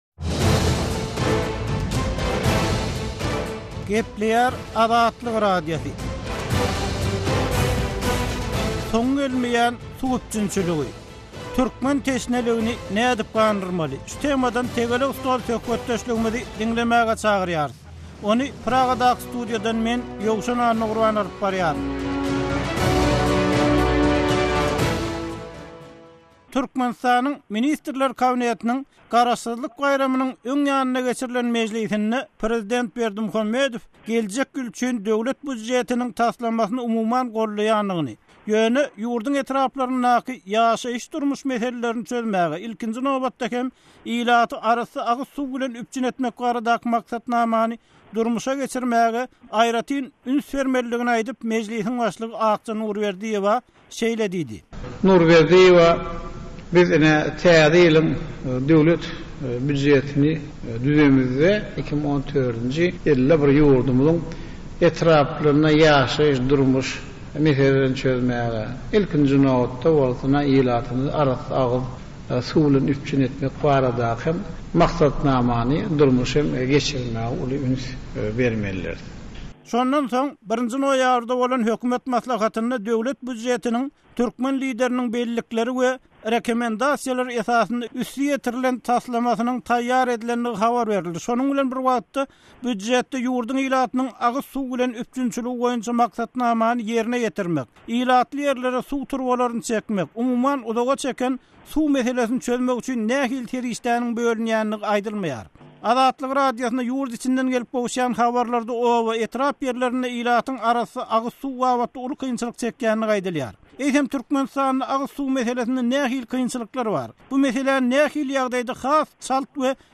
Eýsem Türkmenistanda agyz suwy meselesinde nähili kynçylyklar bar? Bu meseläni nähili ýagdaýda has çalt we netijeli çözüp bolar? Azatlyk Radiosynyň bu meselä bagyşlan “Tegelek stol” söhbetdeşligine ýerli synçylar